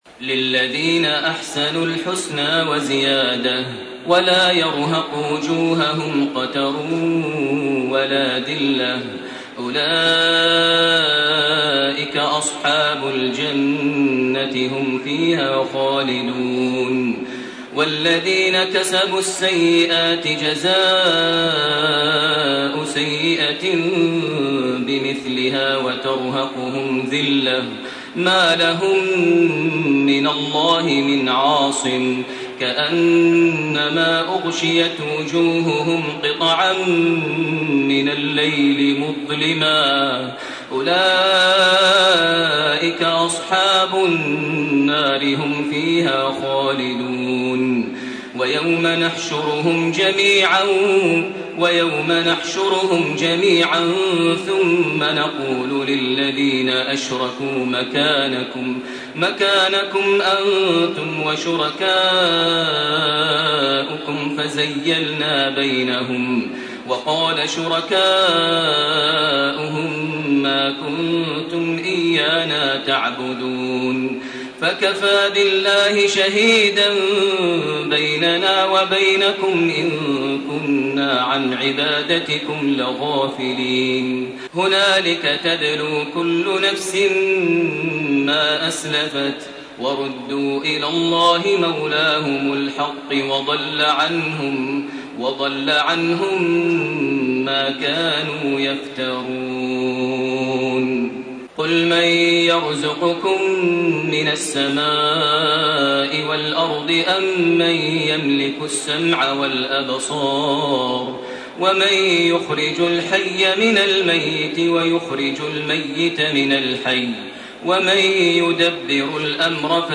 سورة يونس 26 الي آخرها > تراويح ١٤٣٢ > التراويح - تلاوات ماهر المعيقلي